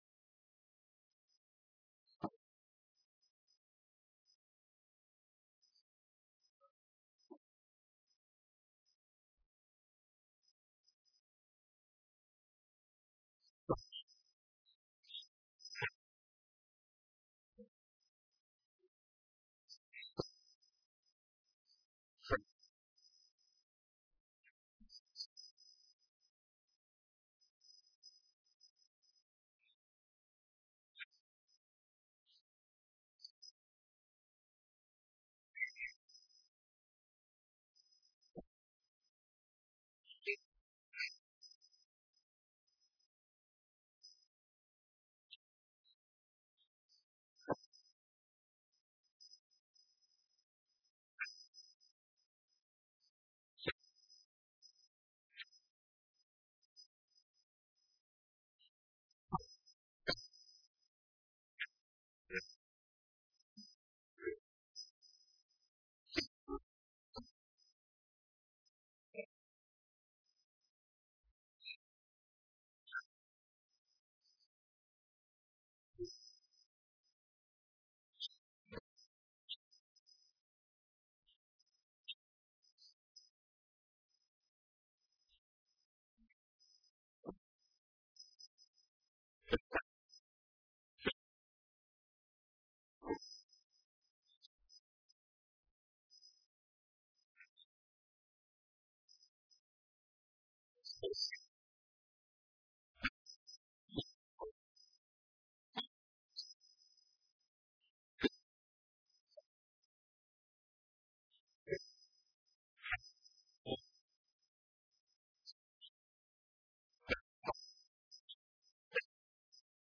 Online Sermons at St. Pauls